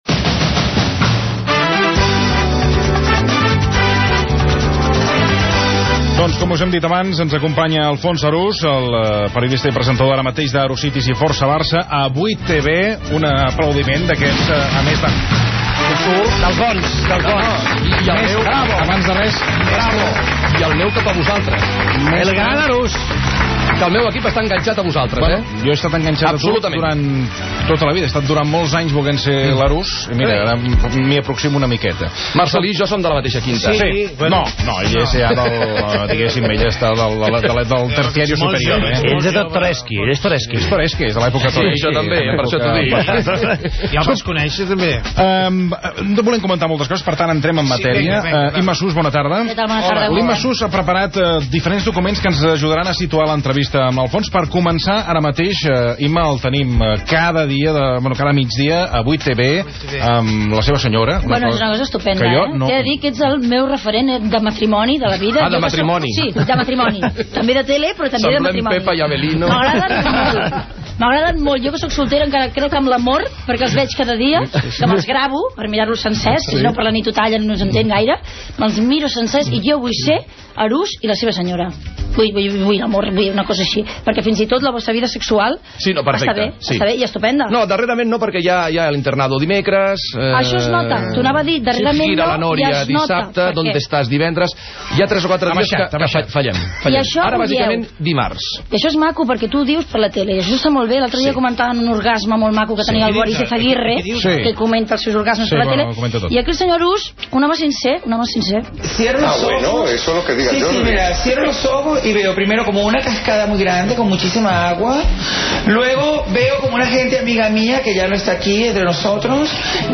5229f107c5fb4d37e1b216de18fcf49ae01c1974.mp3 Títol RAC 1 Emissora RAC 1 Barcelona Cadena RAC Titularitat Privada nacional Nom programa Versió RAC 1 Descripció Entrevista a Alfons Arús.
Gènere radiofònic Entreteniment Presentador/a Clapés